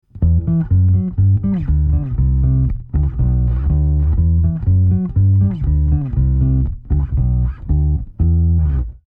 Medium Scale Bass Groove Sound Effects Free Download